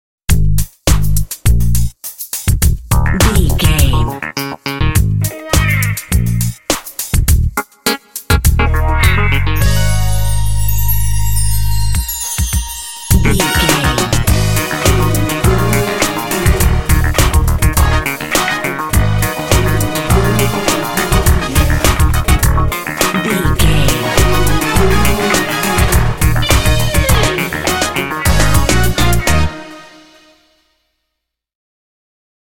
This funky disco groove is great for rhythm games.
Aeolian/Minor
E♭
funky
happy
bouncy
groovy
synthesiser
bass guitar
strings
vocals
drums
Funk